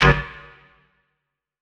Percs
clikhit21.wav